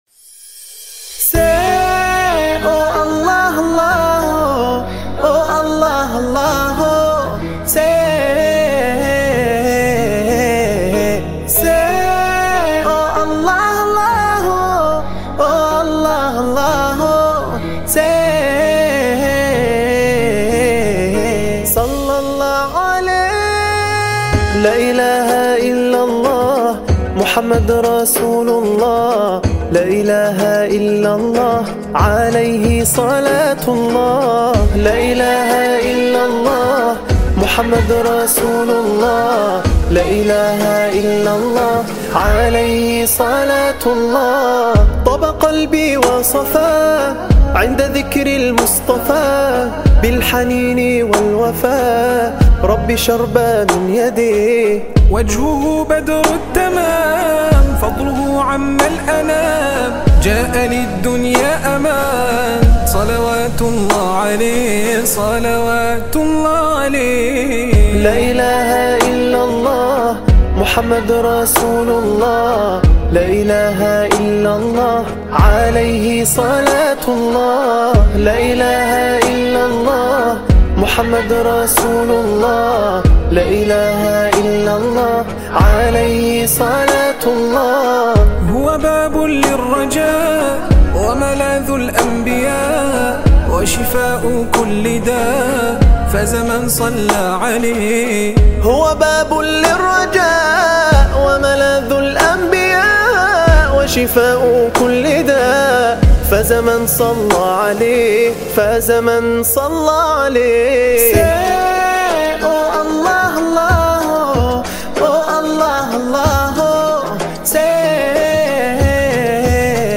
سرودهای ۱۷ ربیع الاول